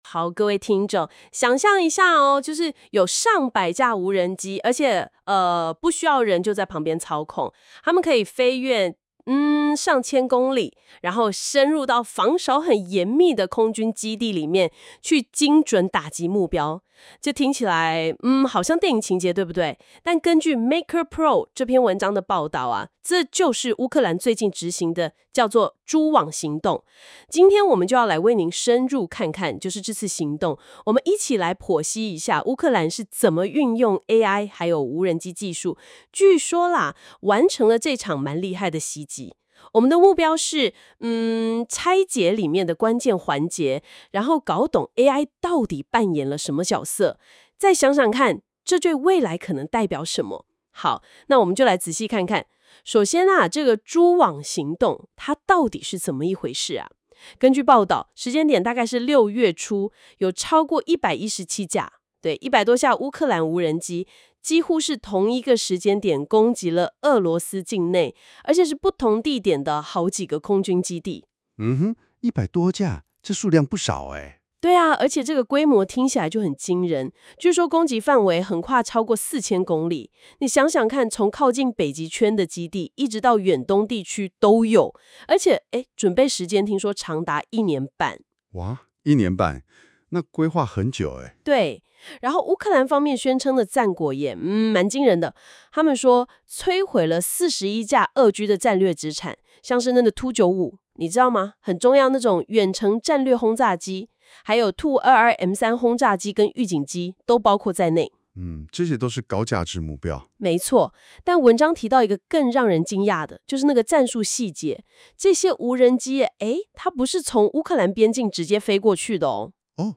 喜歡用聽的？可收聽本文Podcast的精采對話喔（by GenAI）